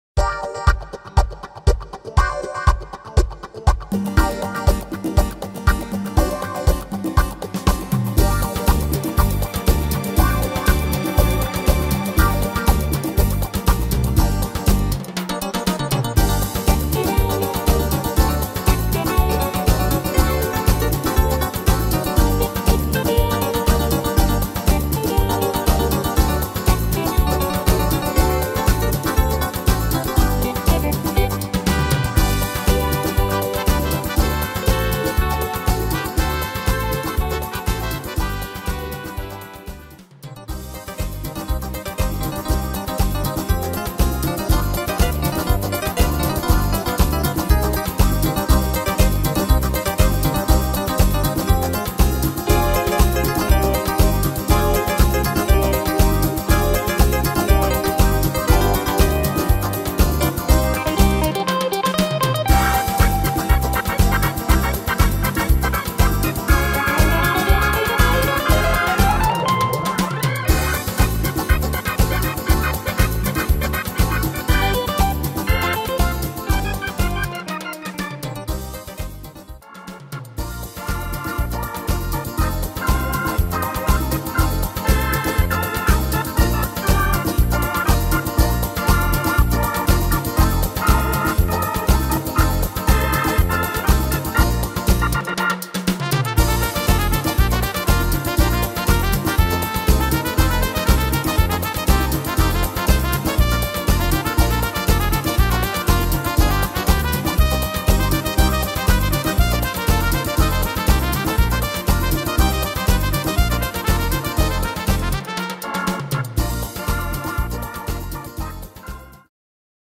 Tempo: 120 / Tonart: G-moll
Inst.